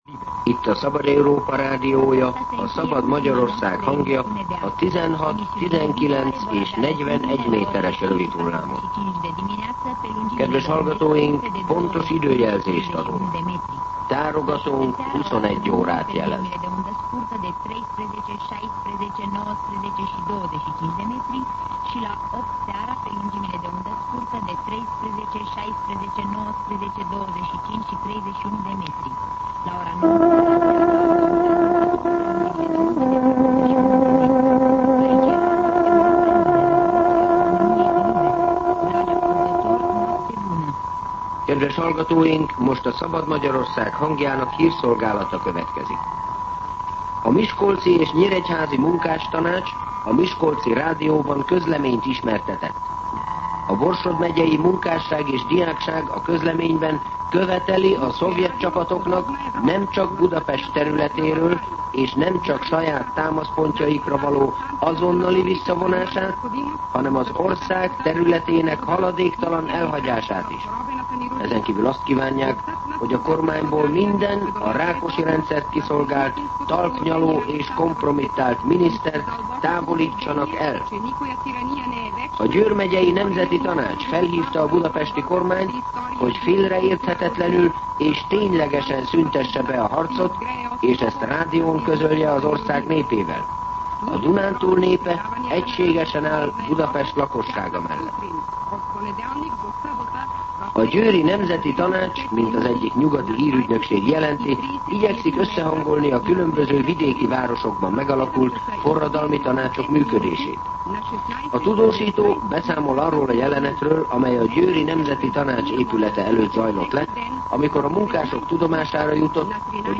21:00 óra. Hírszolgálat
Tárogatónk 21 órát jelez.